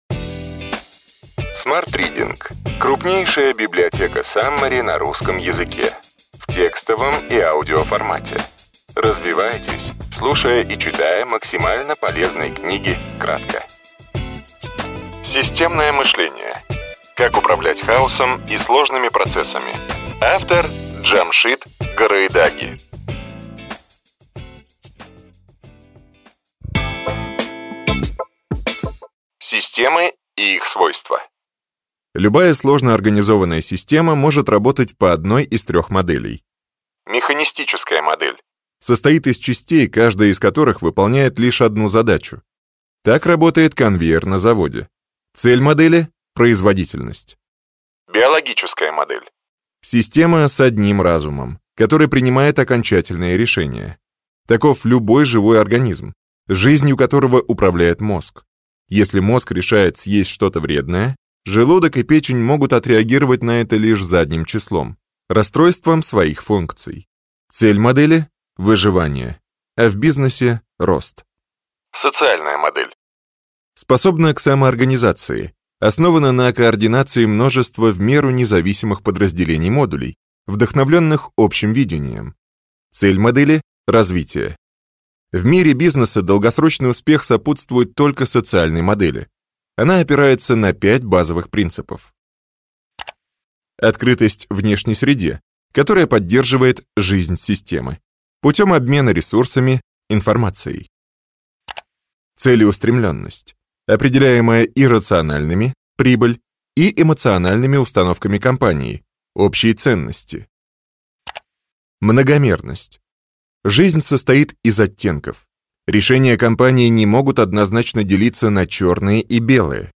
Аудиокнига Ключевые идеи книги: Системное мышление. Как управлять хаосом и сложными процессами. Джамшид Гараедаги | Библиотека аудиокниг